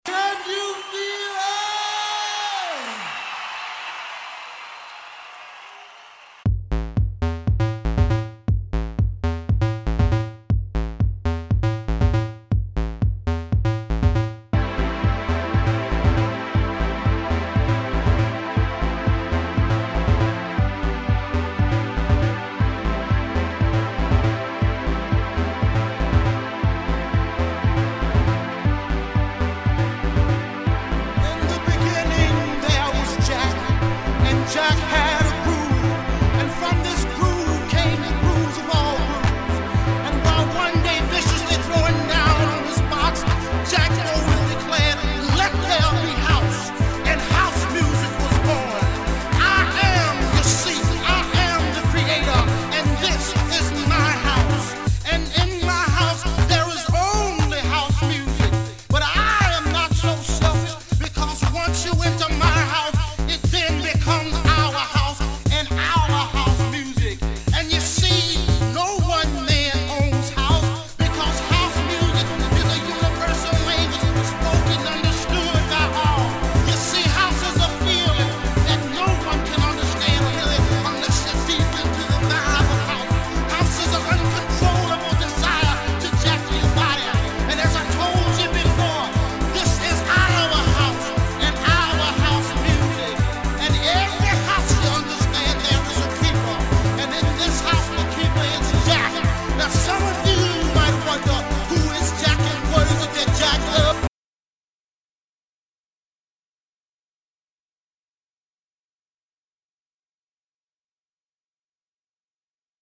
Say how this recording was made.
Recorded from the main outs of DN2, DT2 & TR-1000.